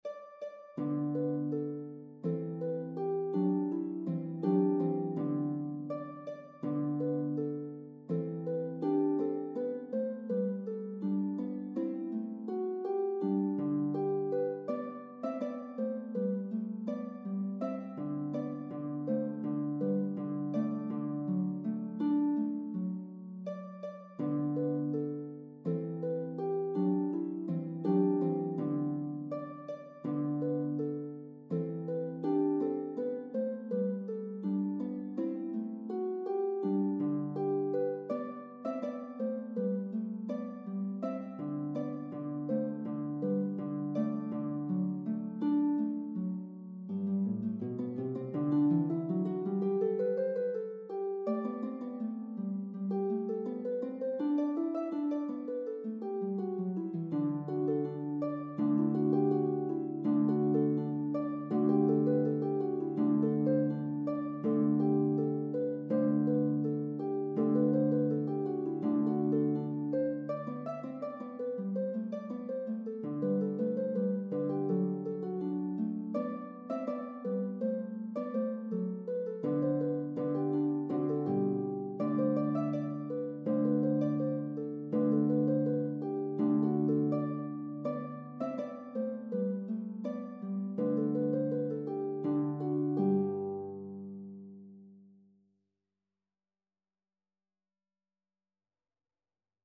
This pastoral folk ditty
Enjoy this delightful tune with its rhythmical surprises.